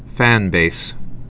(fănbās)